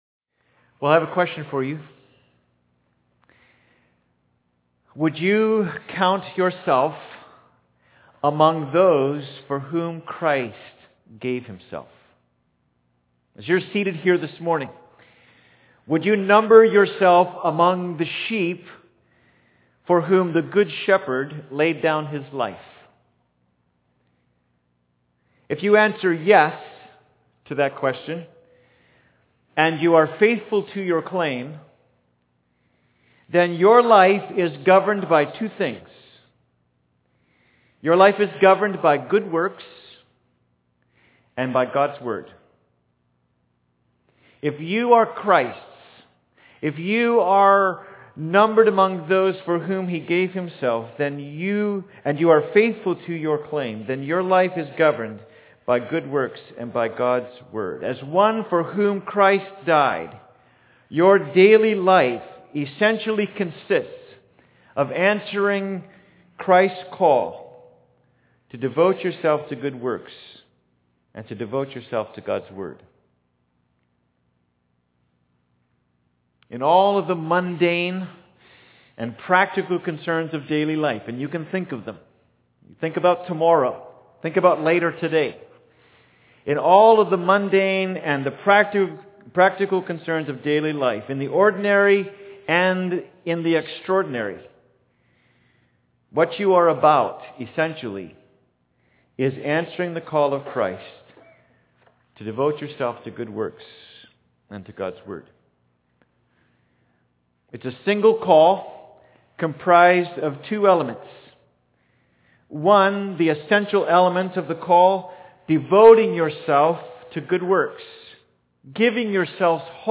Service Type: Sunday Service
Sermon_1.11.m4a